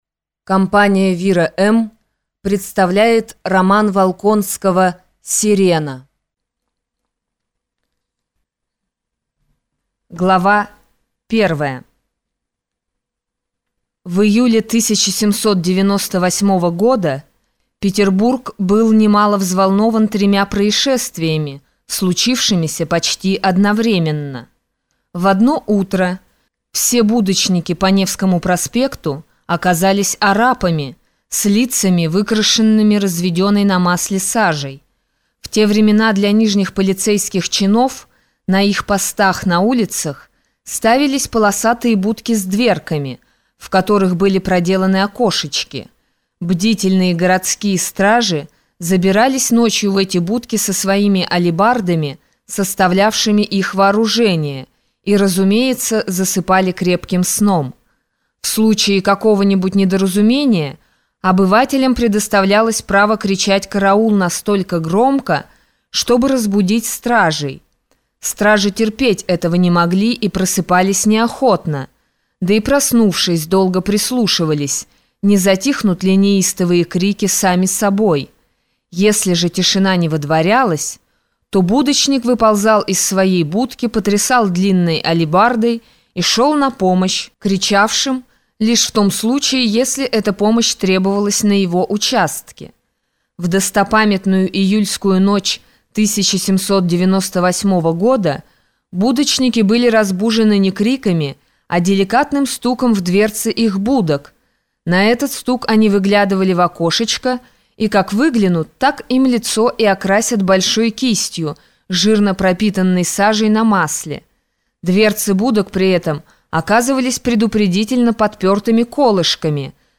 Aудиокнига Сирена